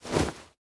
Minecraft Version Minecraft Version snapshot Latest Release | Latest Snapshot snapshot / assets / minecraft / sounds / item / bundle / drop_contents1.ogg Compare With Compare With Latest Release | Latest Snapshot
drop_contents1.ogg